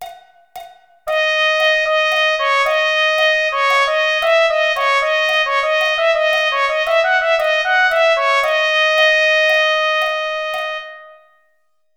Unison Rhythm, mm. 287-292 (MIDI audio file) (
w/click)